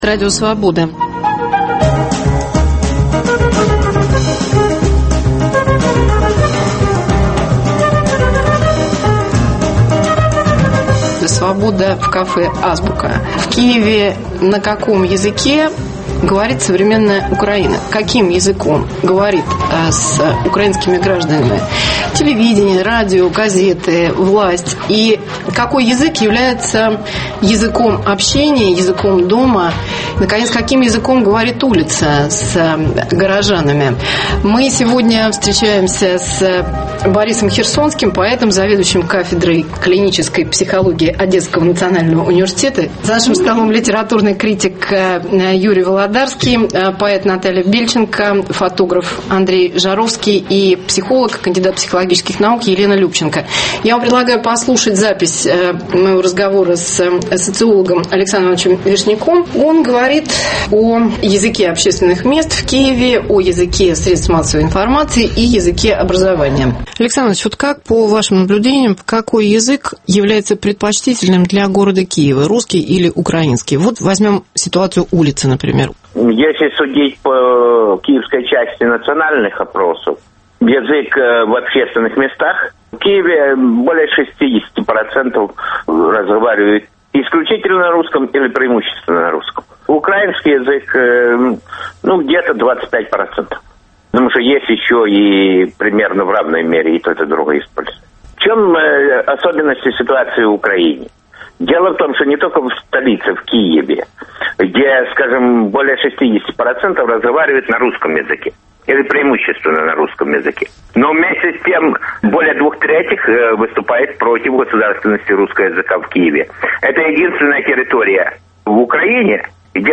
Свобода в клубе «Азбука» в Киеве. Язык современной Украины. Украинский и русский языки в общественном пространстве, медиа, в школе и дома.